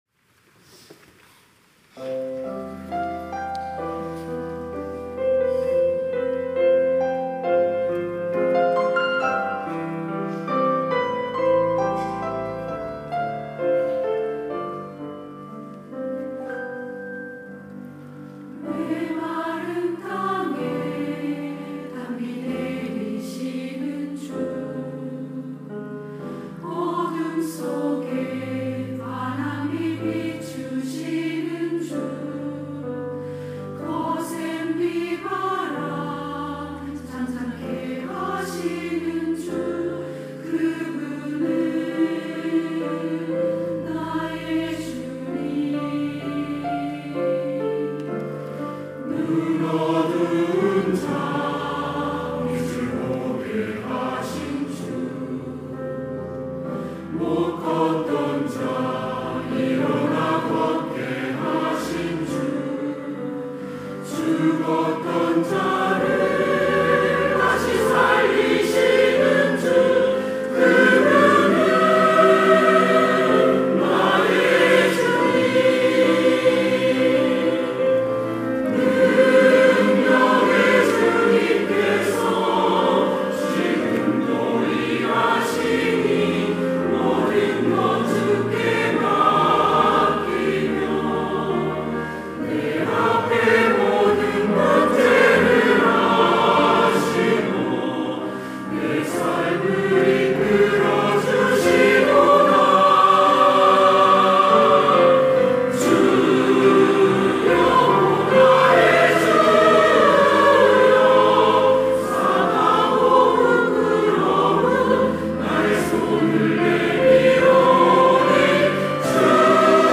시온(주일1부) - 주여 나의 손을 놓지 마소서
찬양대